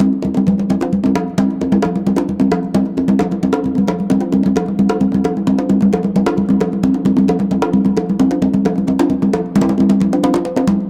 CONGABEAT7-R.wav